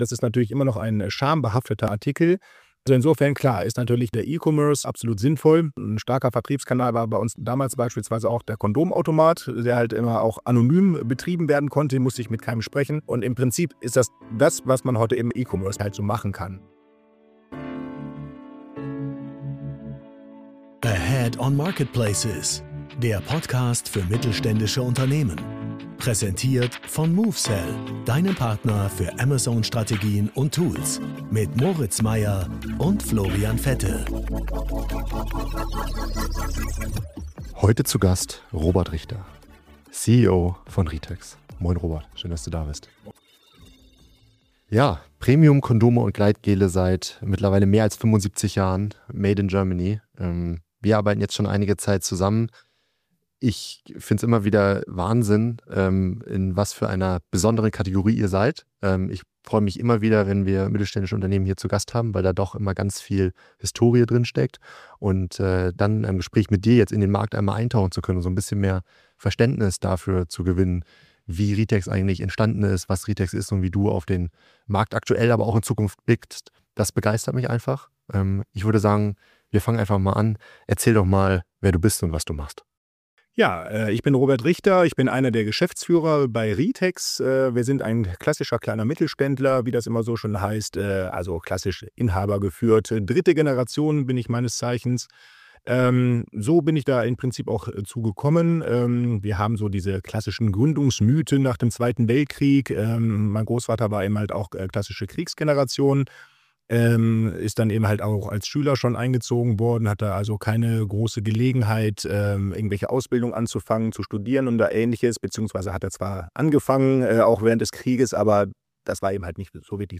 Ein Gespräch über 75 Jahre Familienunternehmen, Kondome als Medizinprodukt und die Frage: Wie behauptet man sich als einer der letzten deutschen Hersteller in einem globalisierten Markt?